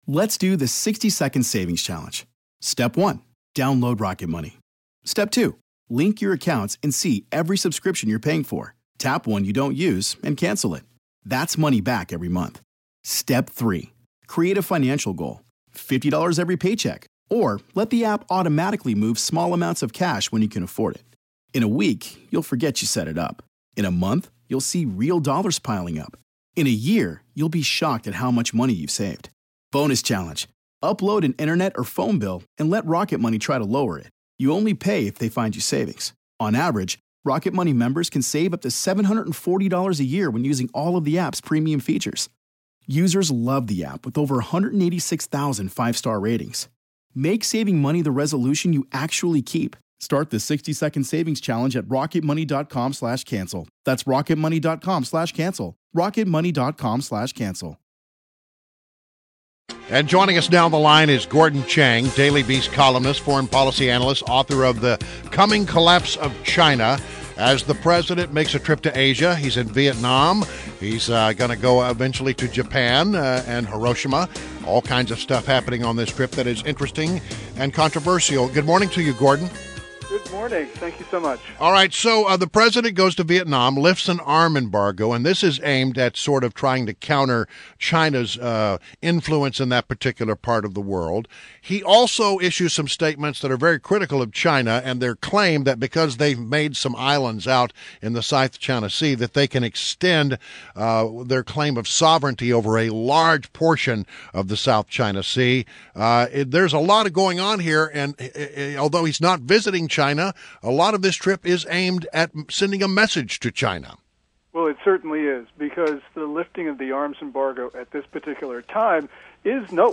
WMAL Interview - GORDON CHANG 05.24.16